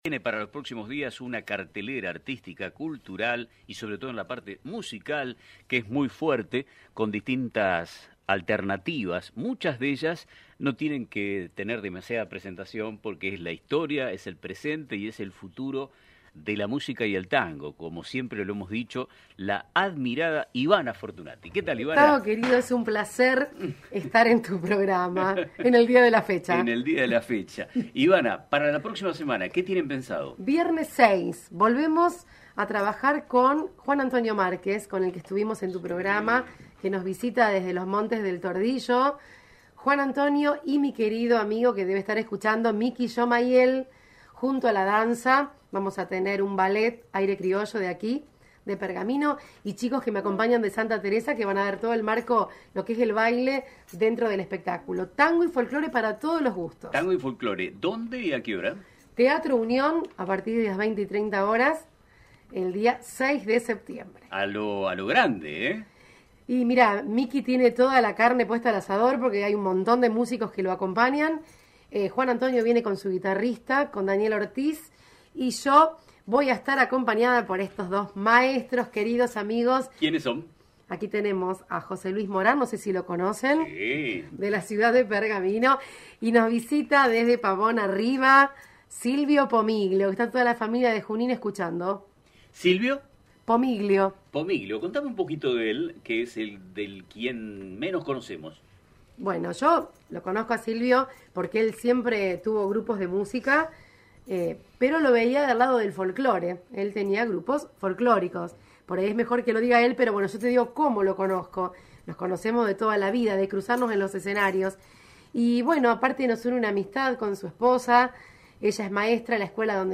En una entrevista exclusiva